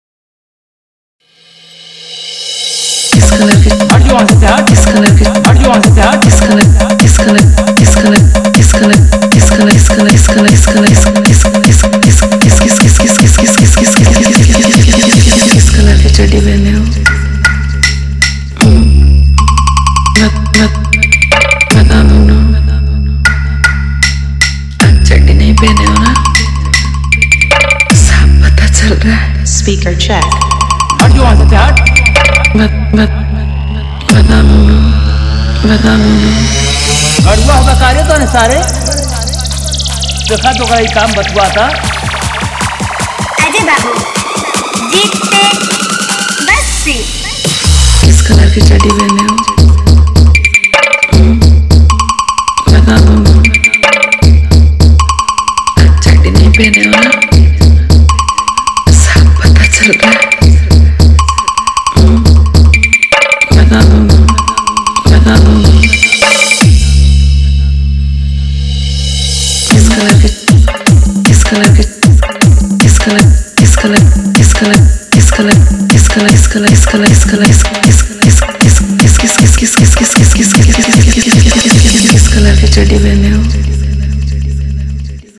Category : Holi 2025 Wala Dj Remix